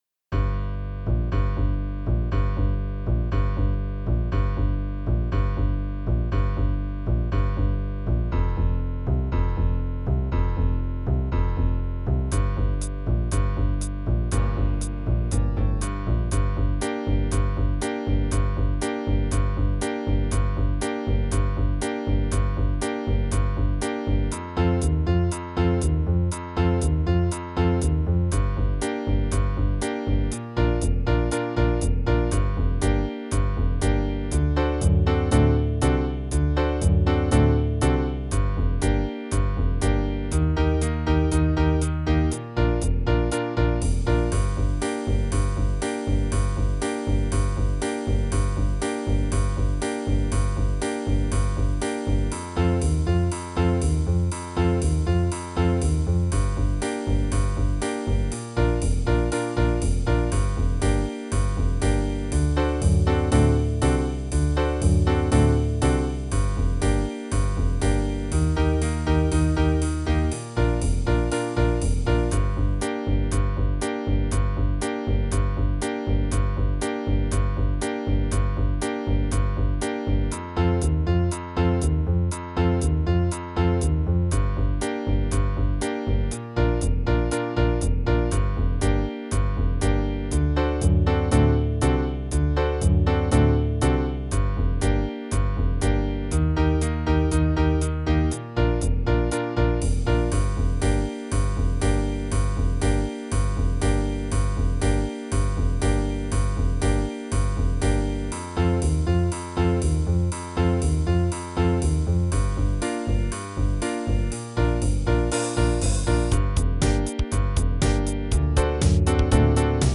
• Le fichier son, SANS la mélodie ==>